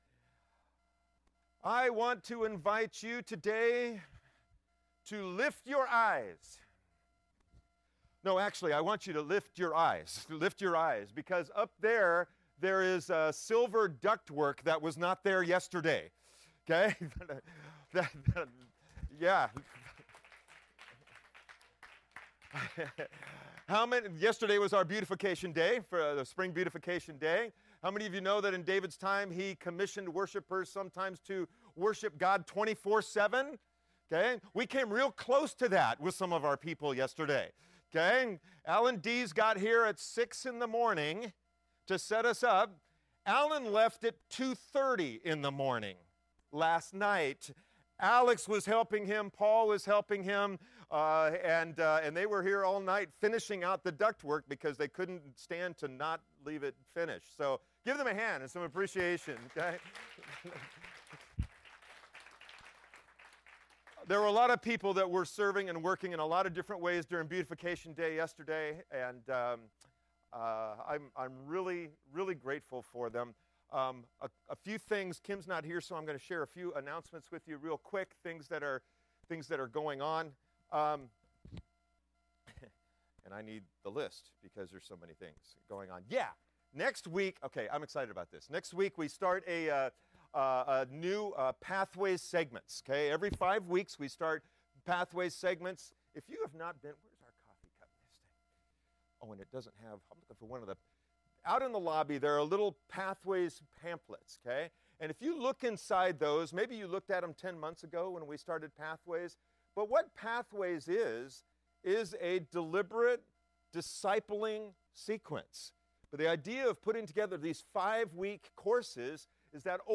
Sermons | Lighthouse Covenant Church